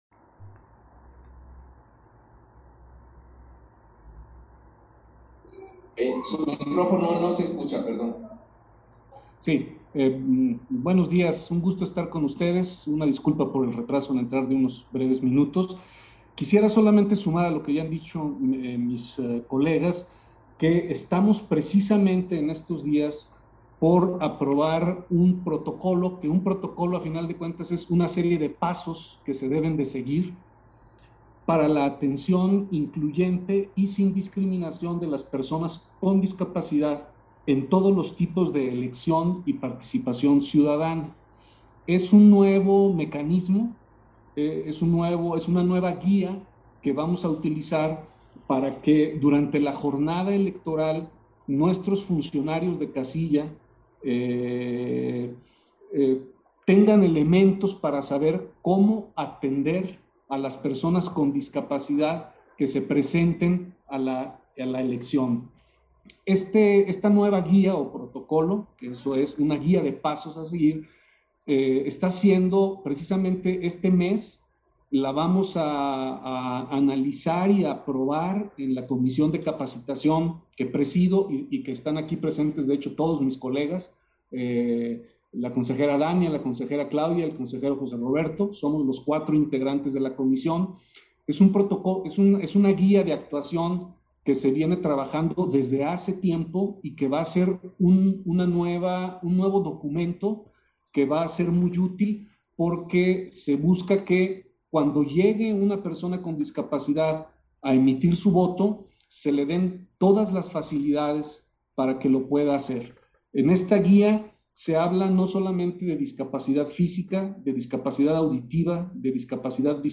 Intervenciones de Consejeras y Consejeros del INE, en la reunión